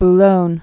(b-lōn, -lônyə) also Bou·logne-sur-Mer (-sûr-mĕr)